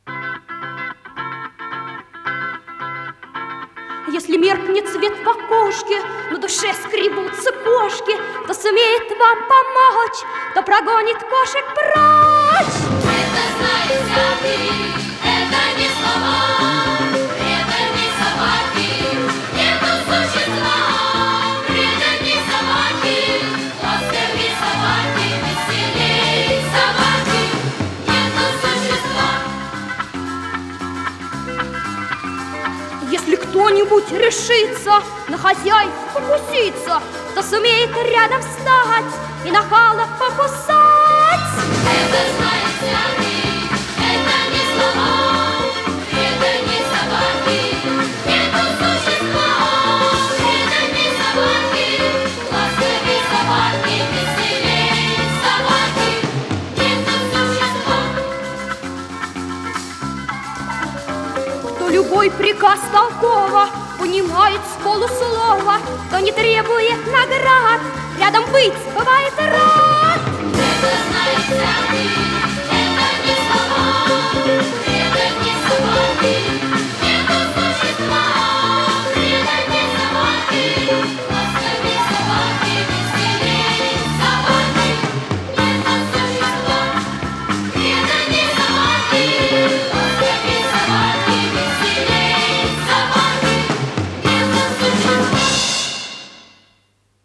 ВариантДубль моно
Тип лентыORWO Typ 104